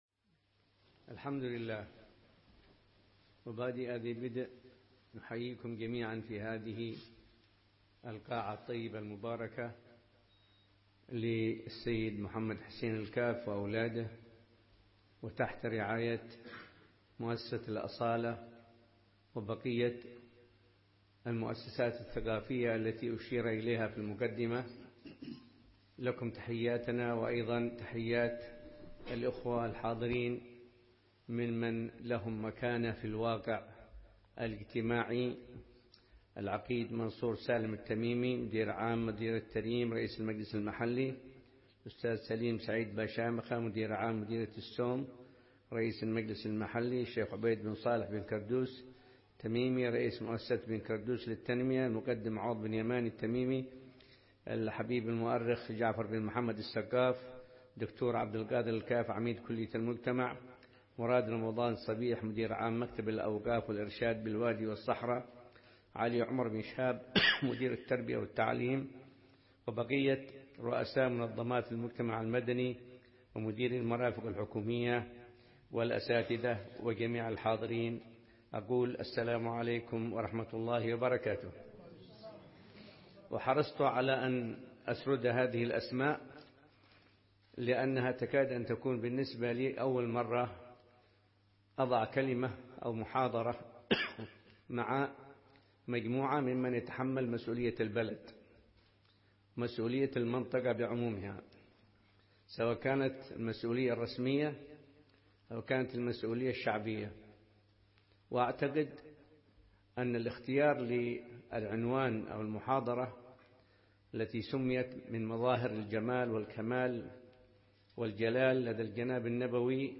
محاضرة
في الأمسية الثقافية التي تنظمها مؤسسة الأصالة للثقافة والتراث والتنمية بمناسبة الذكرى السنوية الثانية للتأسيس